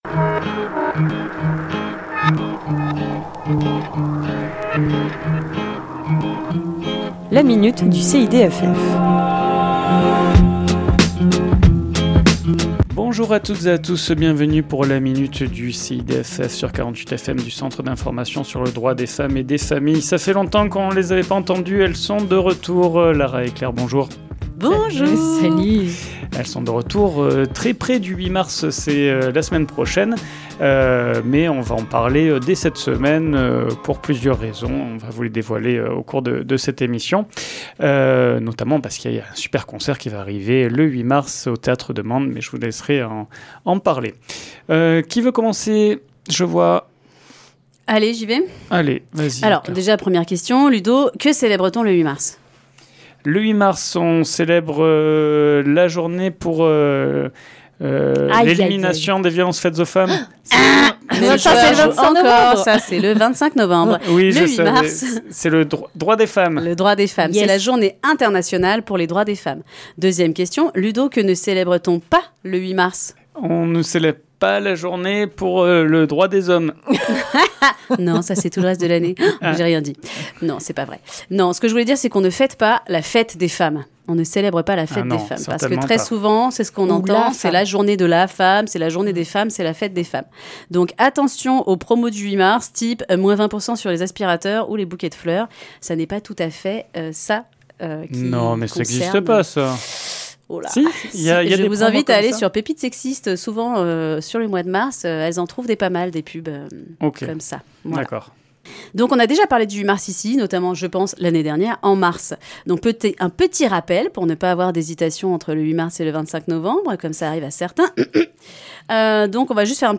Chronique diffusée le lundi 24 février à 11h00 et 17h10